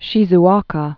(shēzkä)